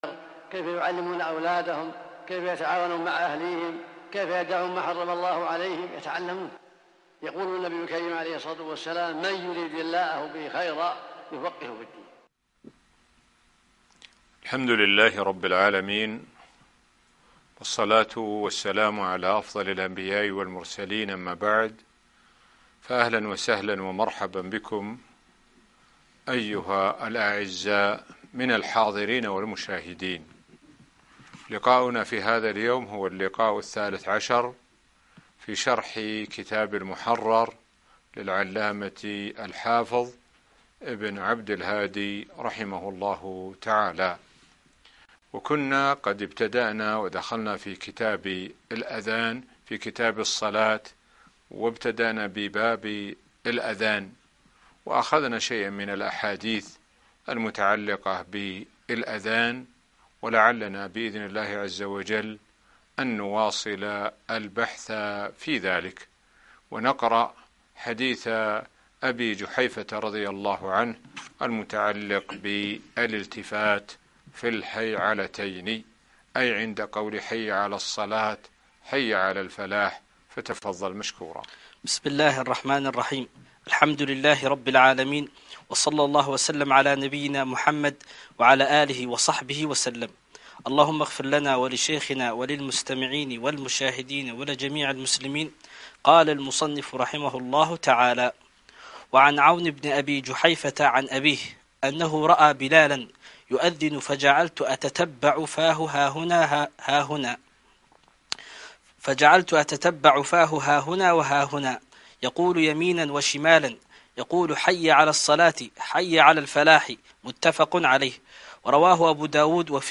المحرر في الحديث (13) الدرس الثالث عشر- البناء العلمي - الشيخ سعد بن ناصر الشثري